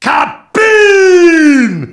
kaboom.wav